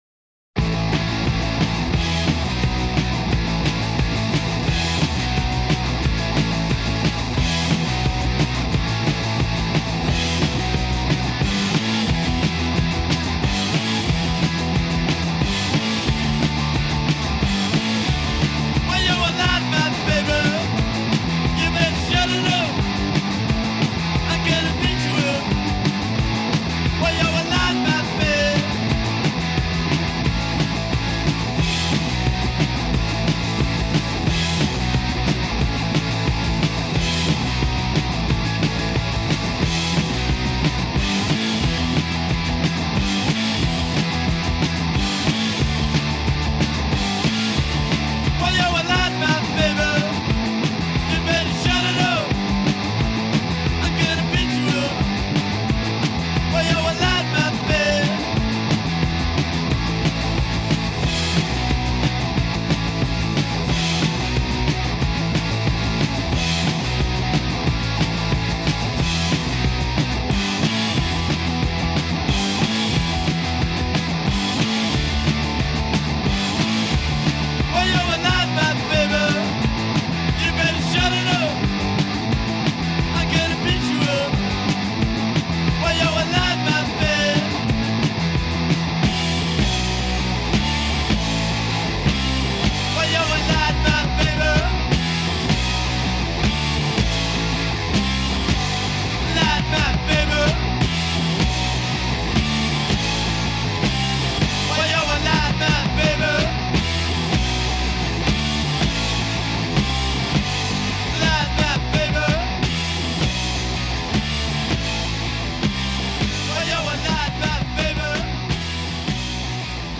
американская рок-группа